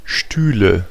Ääntäminen
Ääntäminen Tuntematon aksentti: IPA: /ˈʃtyːlə/ Haettu sana löytyi näillä lähdekielillä: saksa Käännöksiä ei löytynyt valitulle kohdekielelle. Stühle on sanan Stuhl monikko.